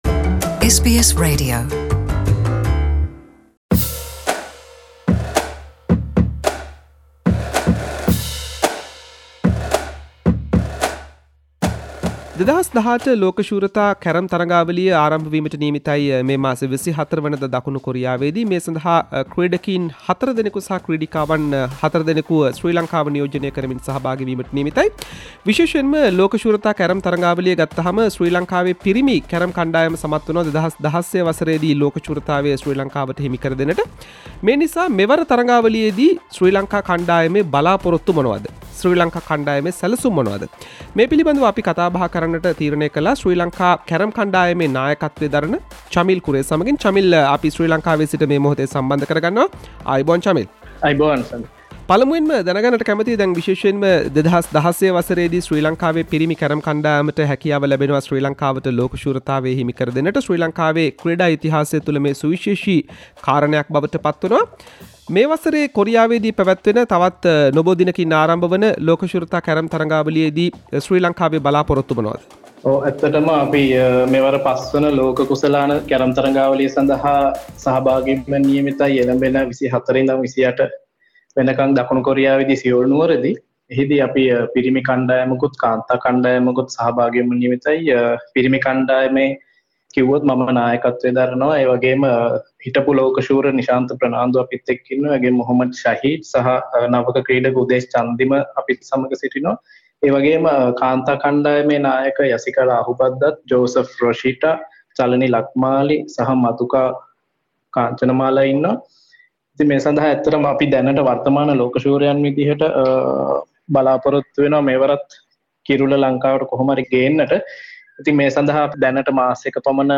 SBS සිංහල වැඩසටහන සමග කල කතා බහක්.